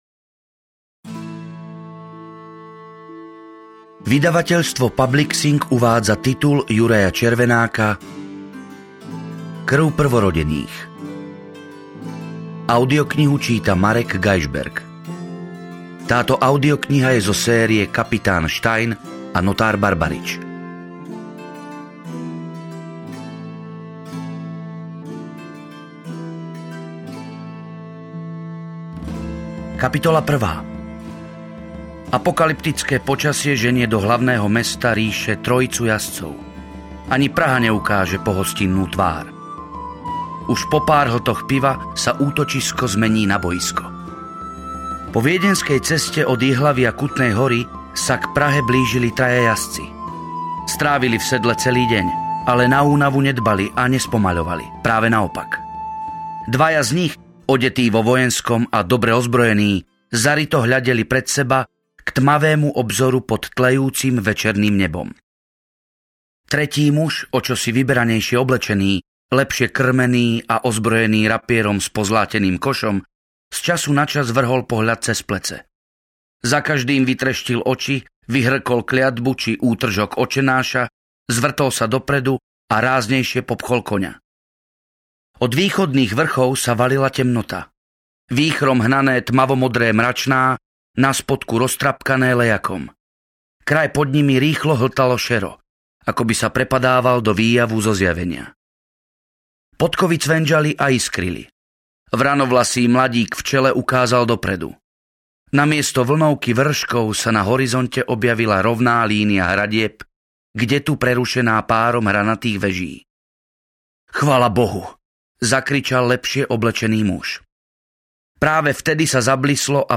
Krv prvorodených audiokniha
Ukázka z knihy